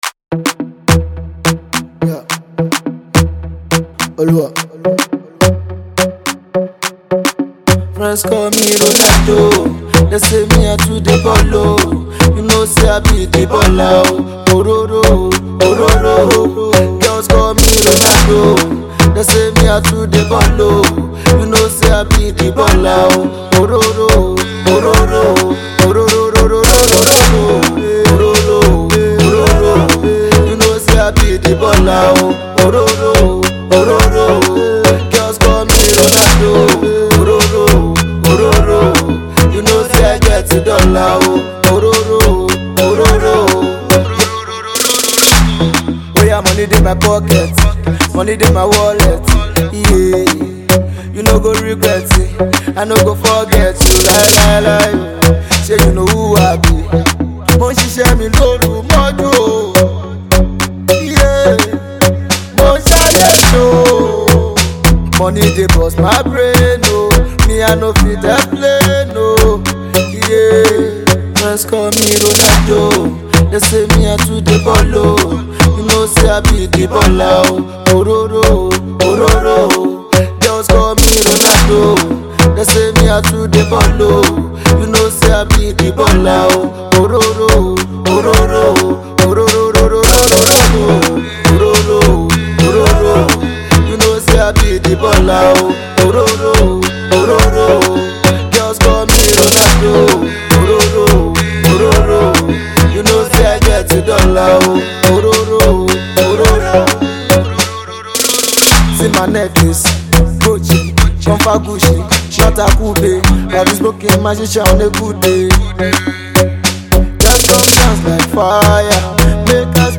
a brain burst,danceable and party rocking jam